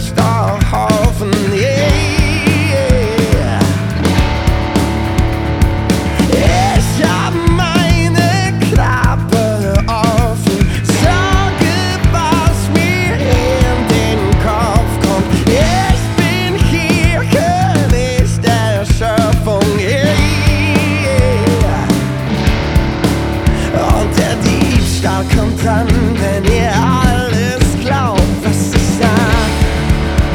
Gesang, Gitarre, Klavier und Backings
eine tief emotionale Atmosphäre